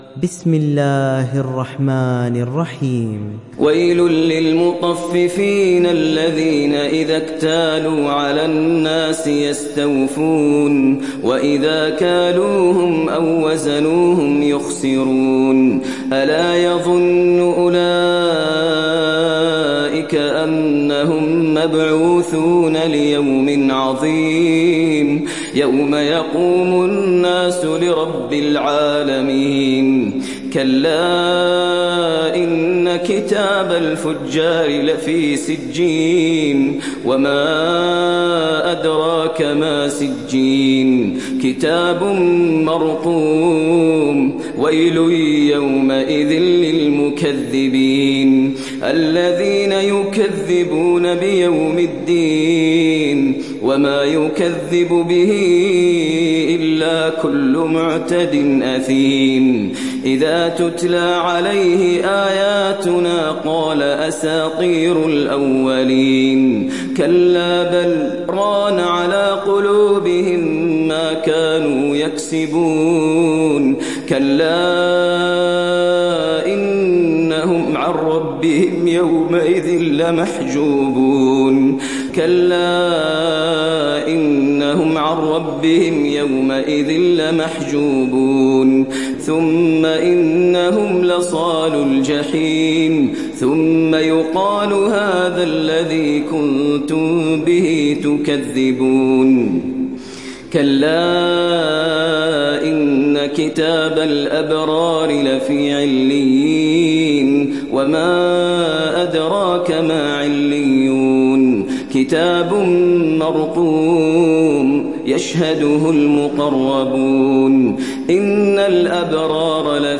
Surat Al Mutaffifin mp3 Download Maher Al Muaiqly (Riwayat Hafs)